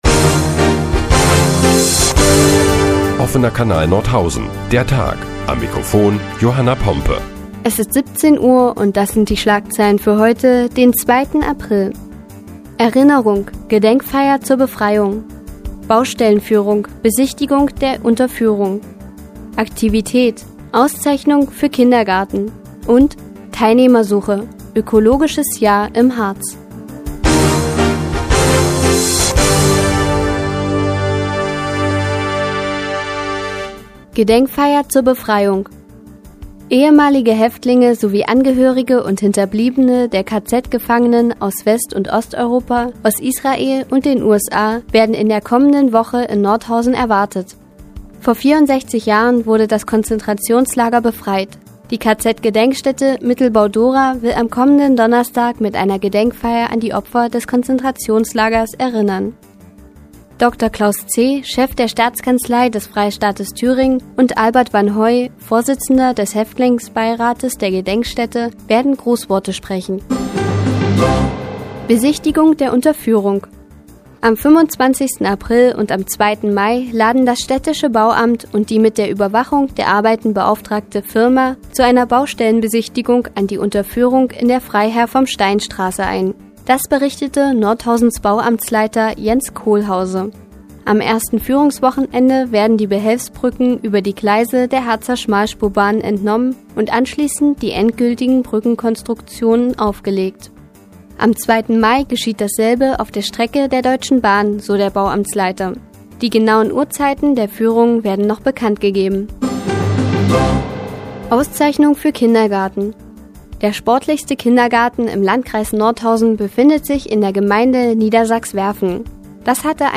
Die tägliche Nachrichtensendung des OKN ist nun auch in der nnz zu hören. Heute geht es unter anderem um Gedenkveranstaltungen im ehemaligen KZ Mittelbau-Dora und eine Auszeichnung für den sportlichsten Kindergarten.